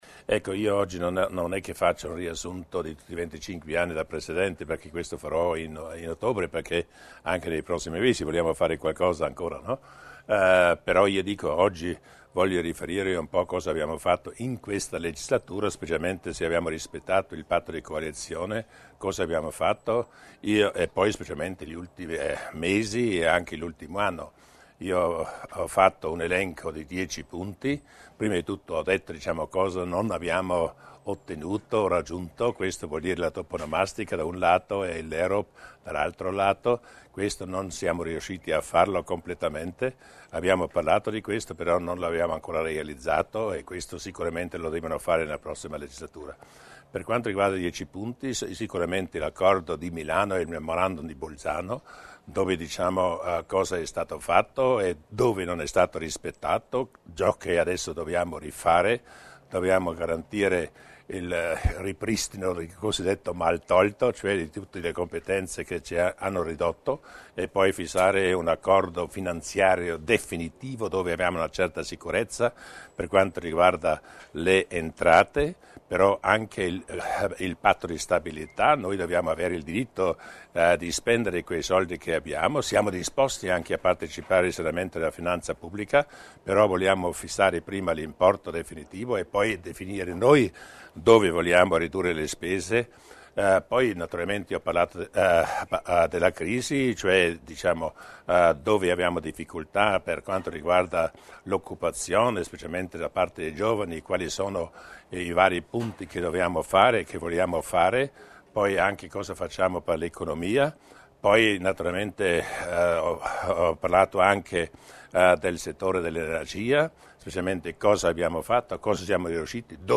L'Accordo di Milano e i rapporti con il governo centrale, le misure intraprese e i programmi da attuare per combattere la crisi, le intese con lo Stato e i provvedimenti in tema di formazione. Questi i primi argomenti trattati oggi (16 agosto) dal presidente della Provincia Luis Durnwalder nel corso della consueta conferenza stampa estiva a Falzes.